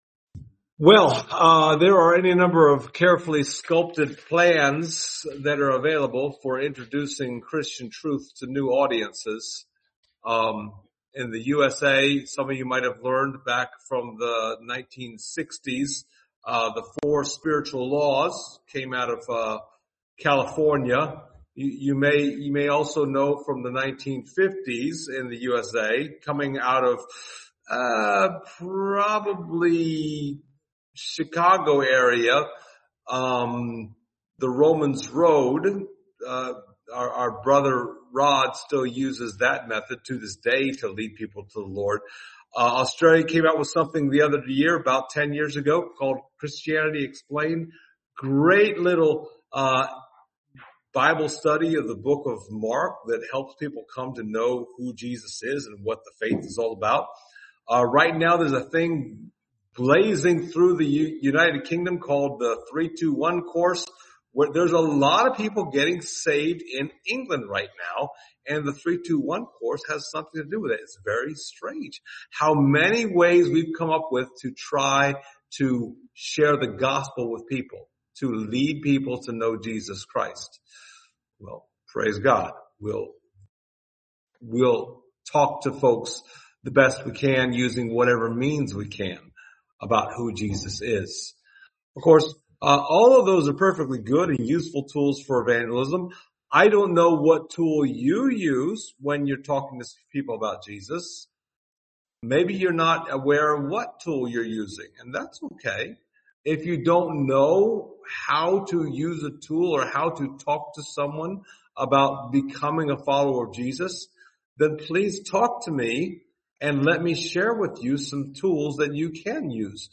Thessalonians 1:4-8 Service Type: Sunday Morning « FROM THE VERY BEGINNING Converted from What to Whom?